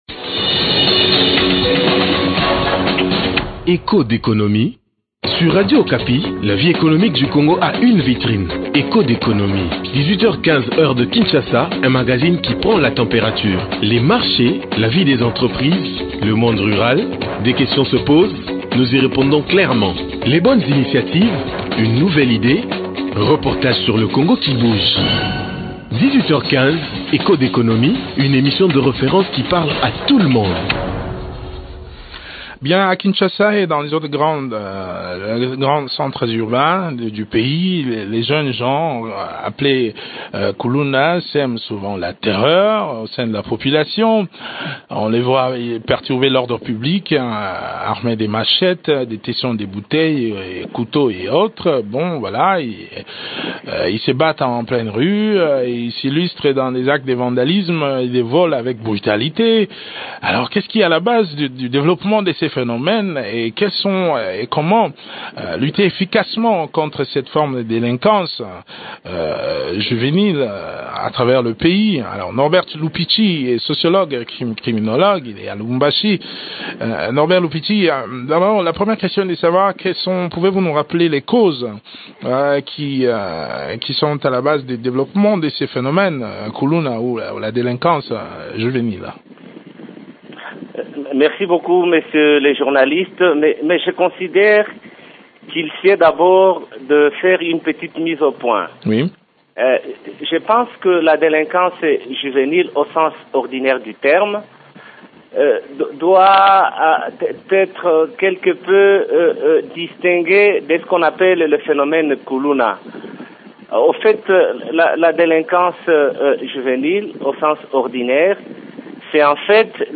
criminologue.